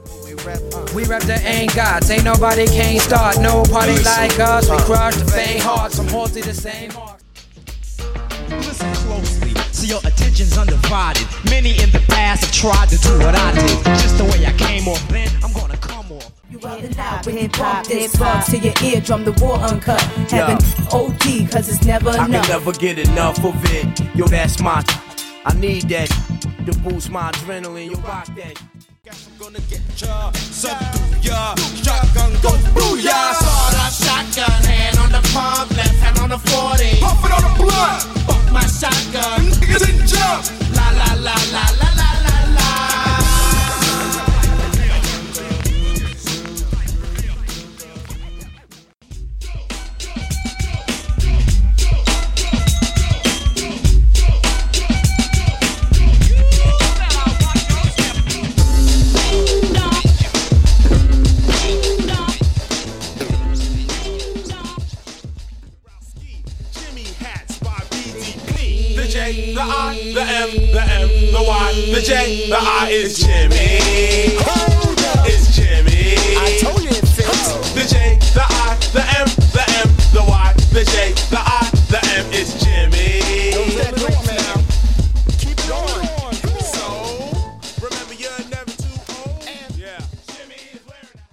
Genres: DANCE , RE-DRUM , TOP40
Clean BPM: 120 Time